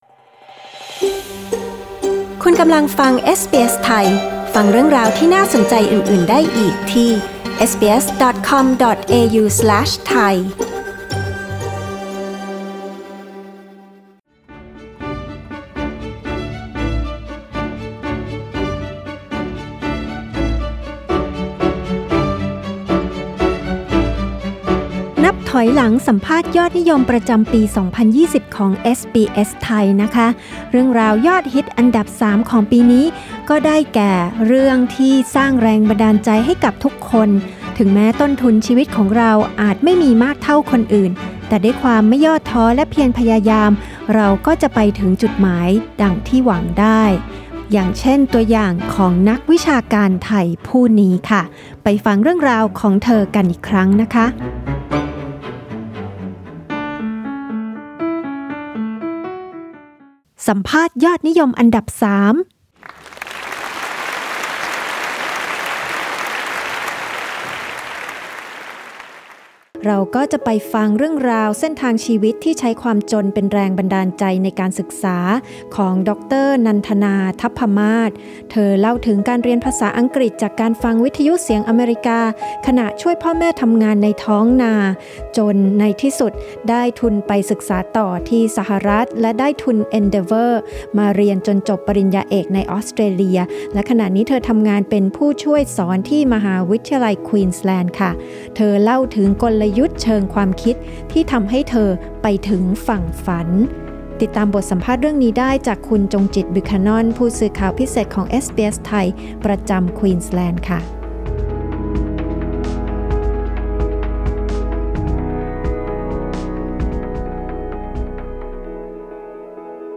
สัมภาษณ์ยอดฮิตติดอันดับ 3 ประจำปี 2020 จากเด็กท้องนาสู่นักเรียนนอก Source: supplied/SBS Thai/Unsplash